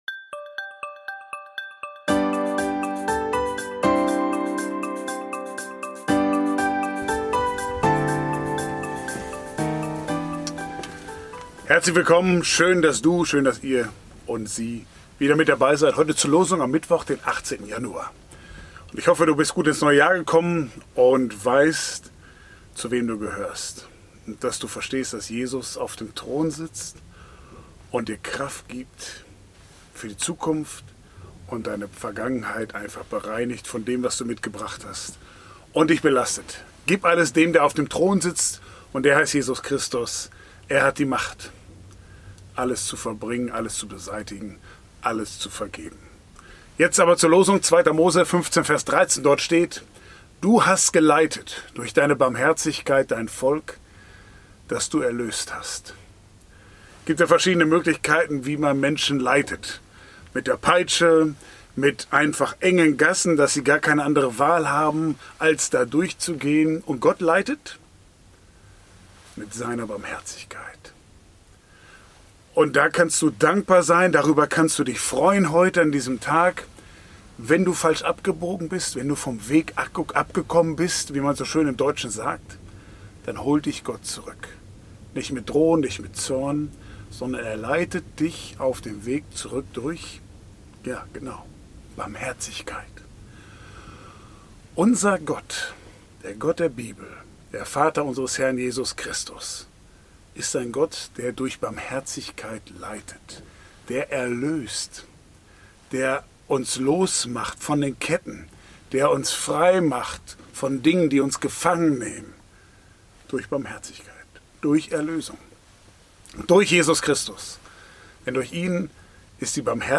Ein täglicher Impuls